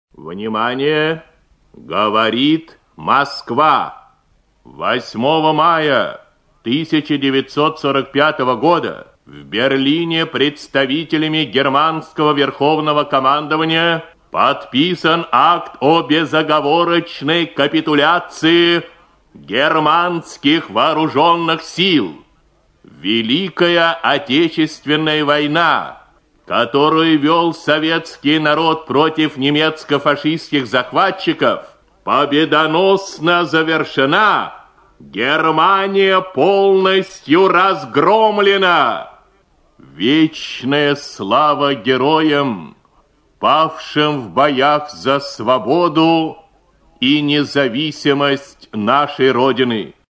Приказ Верховного главнокомандующего 9 мая 1945 г. Читает диктор Ю.Б. Левитан. Запись 1945 г.